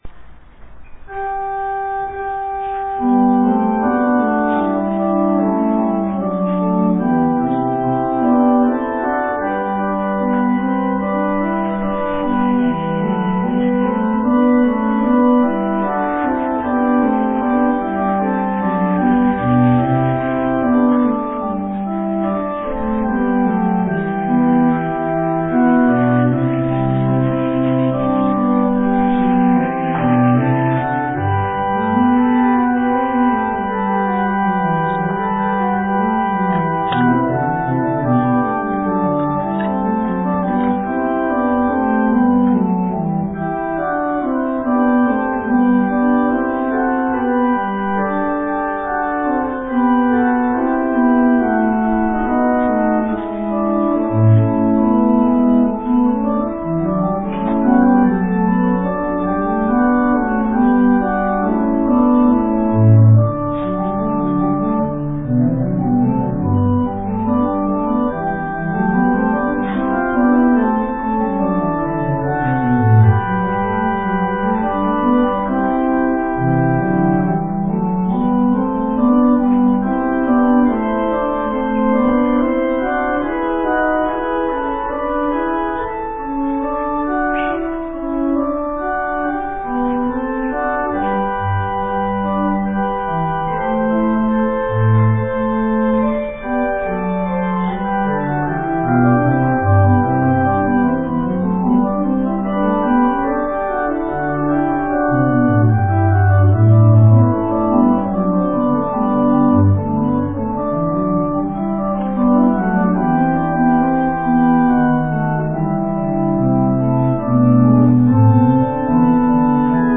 過去の礼拝で演奏された前奏曲です